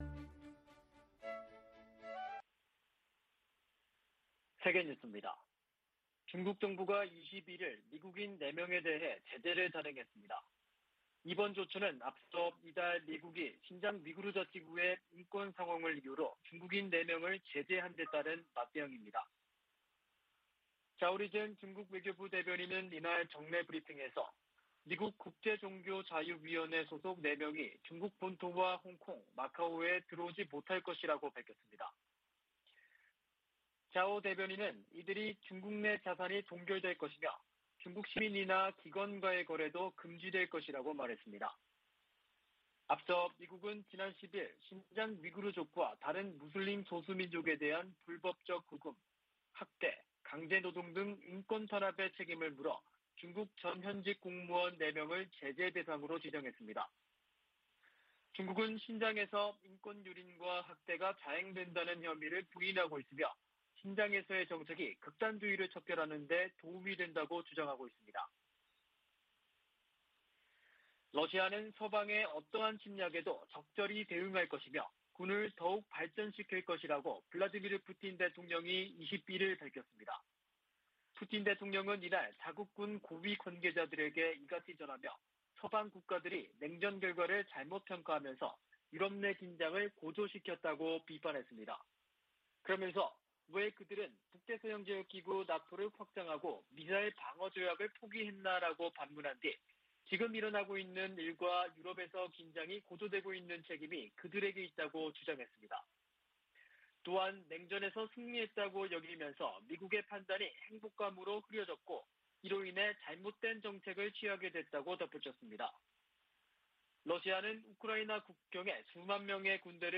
VOA 한국어 아침 뉴스 프로그램 '워싱턴 뉴스 광장' 2021년 12월 22일 방송입니다. 북한은 미사일 개발 등 대규모 군사비 지출로 만성적인 경제난을 겪고 있다고 미 CIA가 분석했습니다. 미국의 전문가들은 북한이 ICBM 대기권 재진입과 핵탄두 소형화 등 핵심 기술을 보유했는지에 엇갈린 견해를 내놓고 있습니다. '오미크론' 변이 출현으로 북-중 교역 재개가 지연됨에 따라 북한 주민들이 겨울나기에 한층 어려움을 겪고 있습니다.